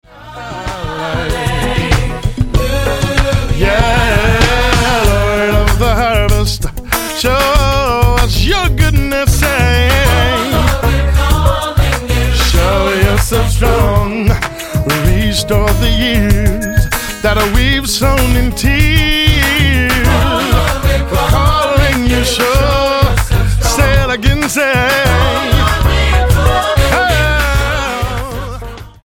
STYLE: Gospel
high-octane, life-changing urban worship songs